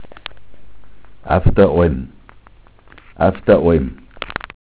Stoffsammlung: Mundart: af da eulm Quelle Vollmann: ...